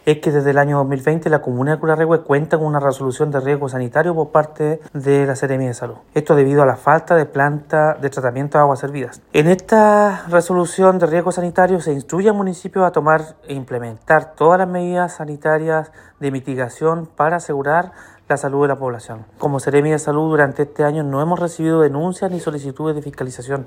Por su parte, el seremi de Salud de La Araucanía, Andrés Cuyul, señaló que desde 2020 la zona cuenta con una resolución de riesgo sanitario, donde se instruye al municipio a tomar medidas
seremi-de-salud.mp3